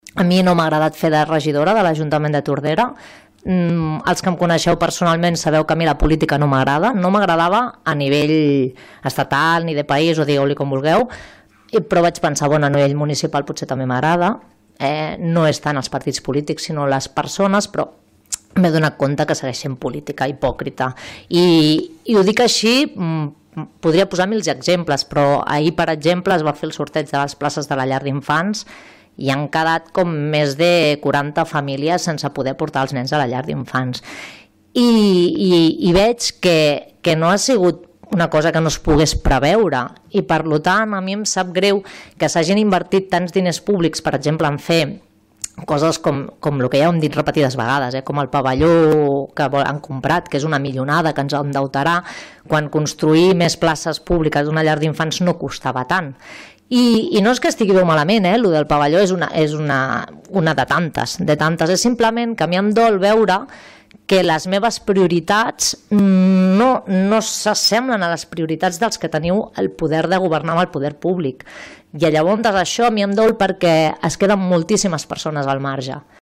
Tots els regidors que plegaven també van realitzar un darrer missatge d’acomiadament.